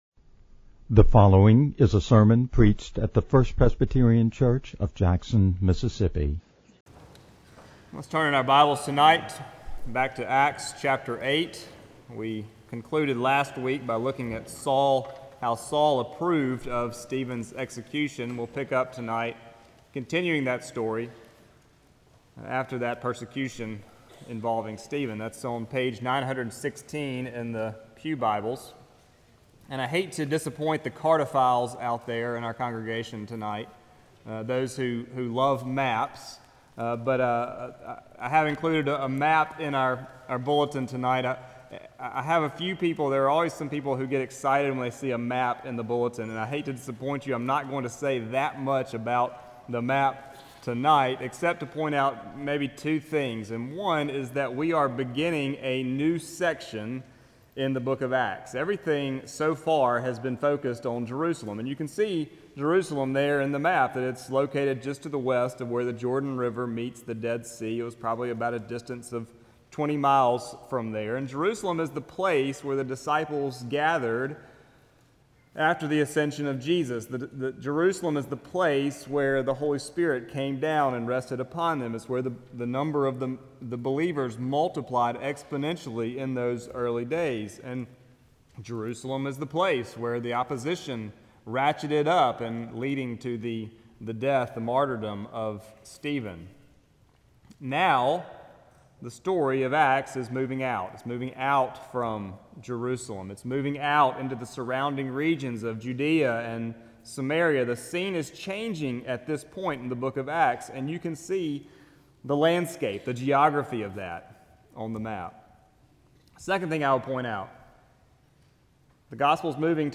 No attempt has been made, however, to alter the basic extemporaneous delivery style, or to produce a grammatically accurate, publication-ready manuscript conforming to an established style template.